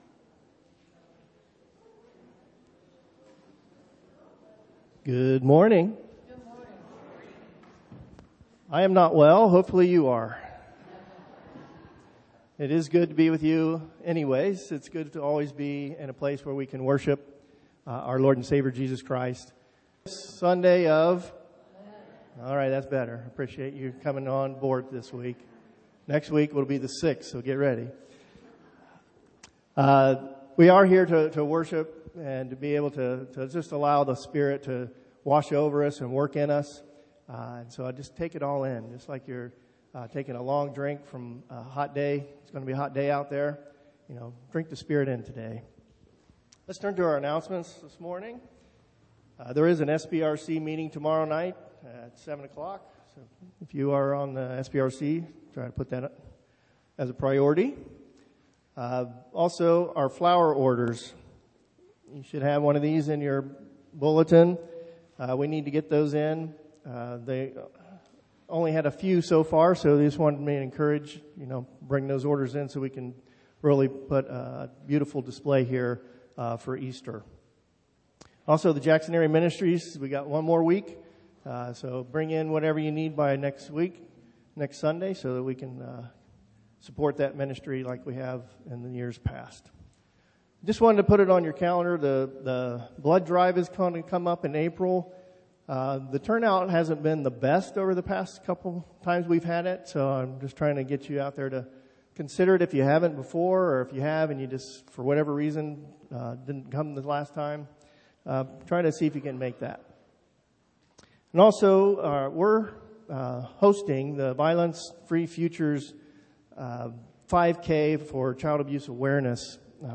Sermons Archive - Faith Community United Methodist Church - Xenia, OH